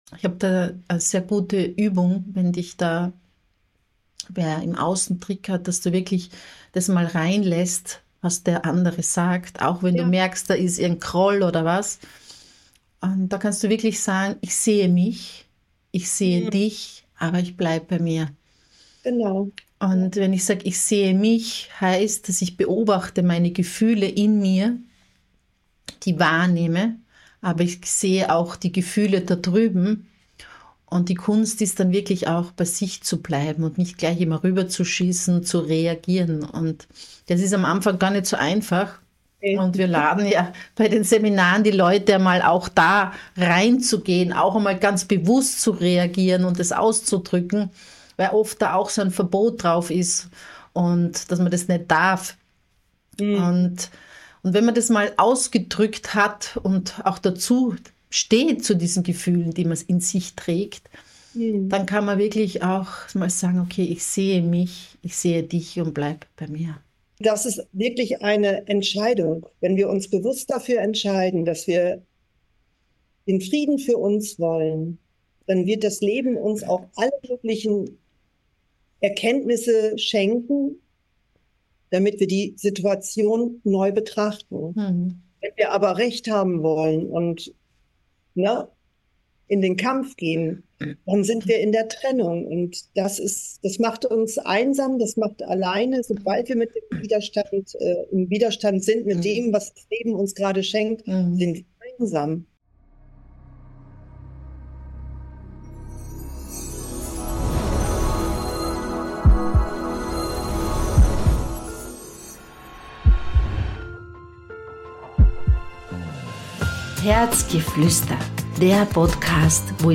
Ein inspirierendes Gespräch über Vertrauen, innere Führung, Krisen als Wendepunkte und den Mut, dem eigenen Herzen zu folgen.